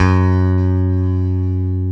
Index of /90_sSampleCDs/Roland L-CD701/BS _E.Bass 2/BS _Rock Bass
BS  ROCKBS07.wav